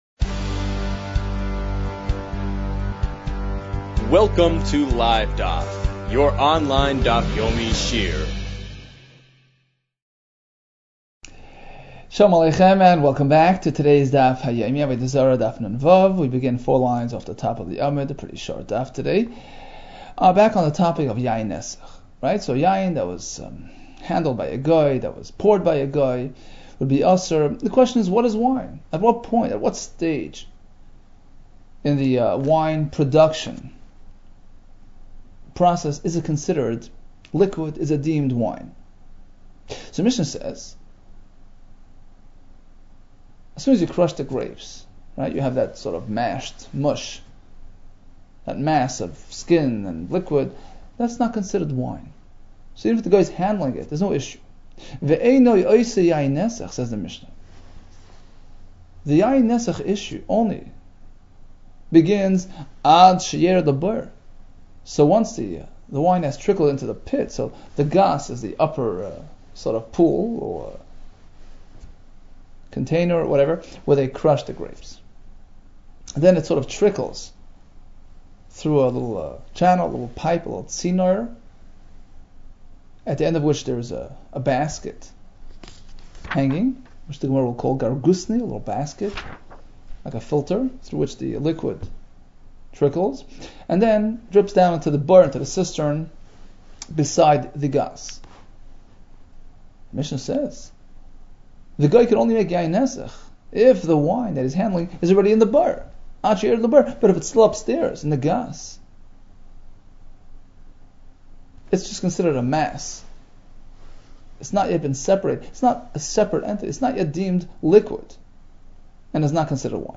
Avodah Zarah 56 - עבודה זרה נו | Daf Yomi Online Shiur | Livedaf